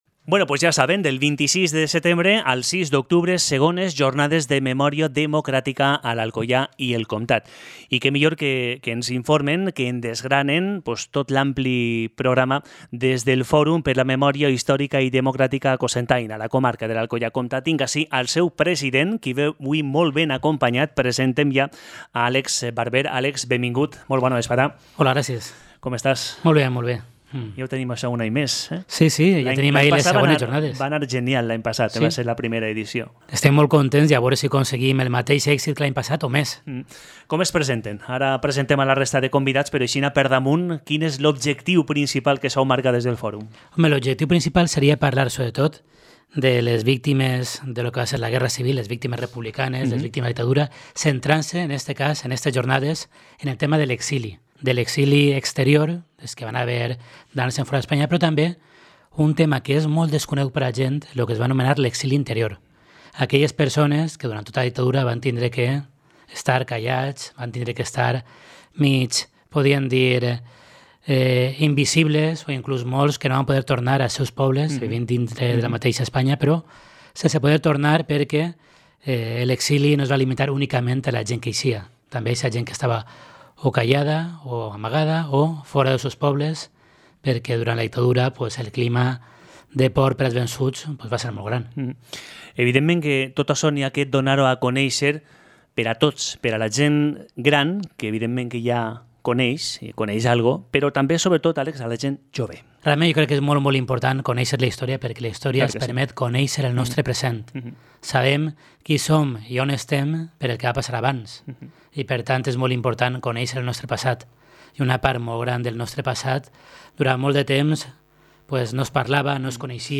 ENTREV-FORUM2.mp3